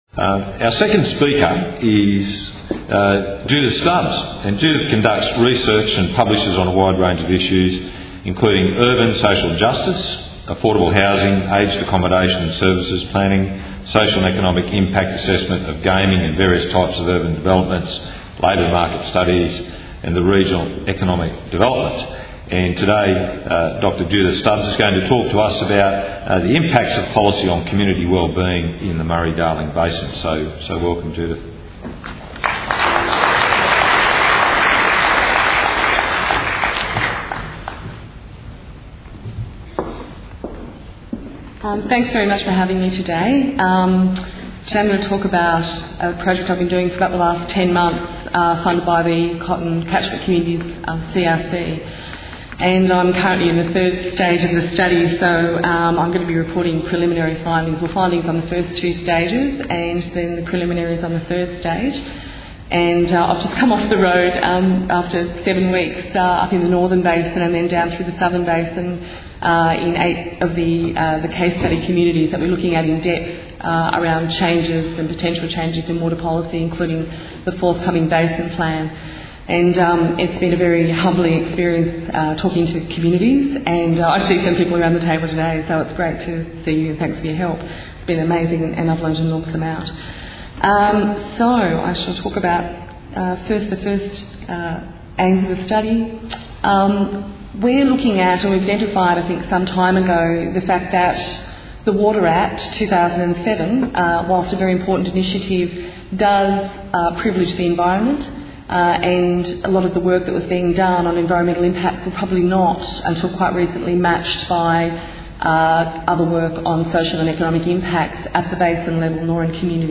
Impacts of policy on community wellbeing in the Murray Darling Basin. - Sustaining Rural Communities Conference 2010 Presentation Audio 30 Min 9.7 MB | Inside Cotton